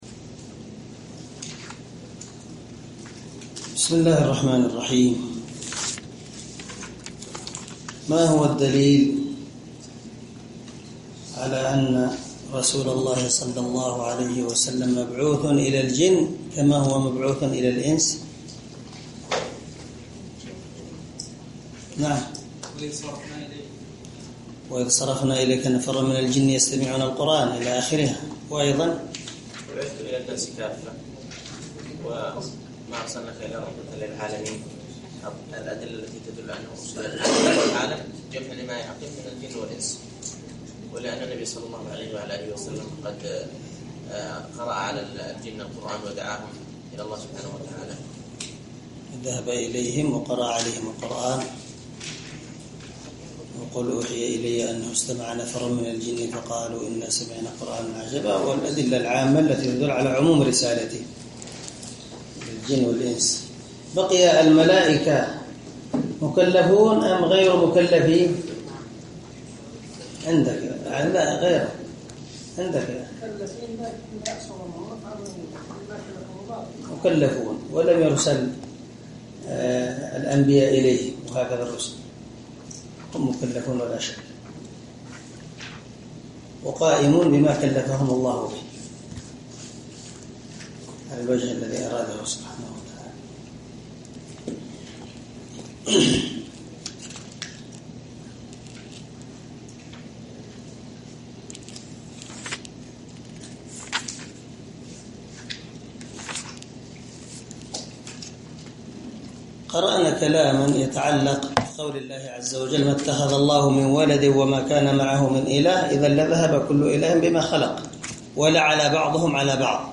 شرح العقيدة الواسطية للعلامة محمد بن خليل هراس رحمه الله – الدرس السادس الثلاثون
دار الحديث- المَحاوِلة- الصبيحة.